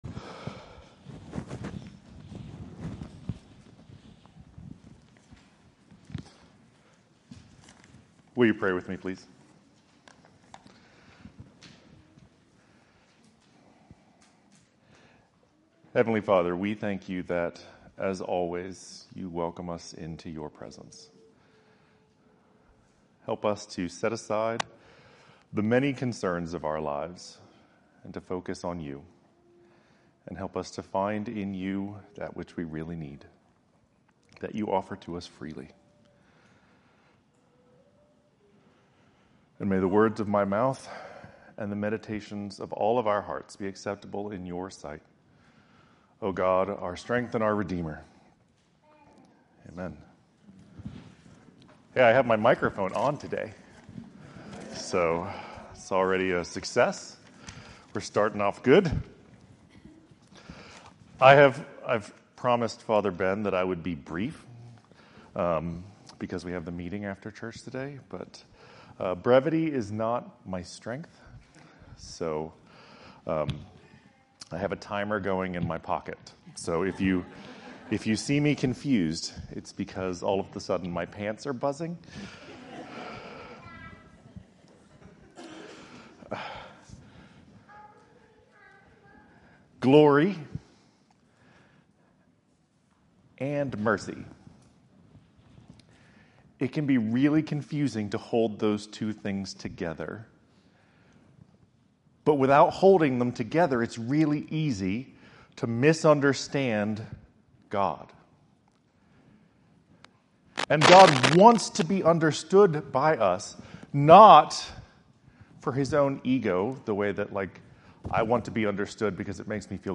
In this sermon from Transfiguration Sunday 2026